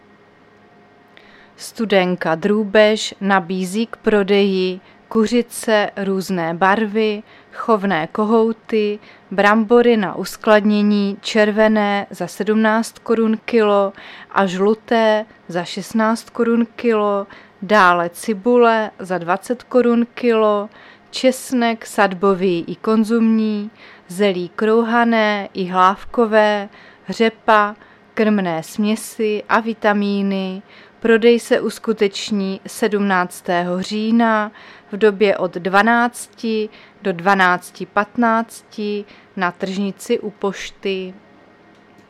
Záznam hlášení místního rozhlasu 16.10.2023
Zařazení: Rozhlas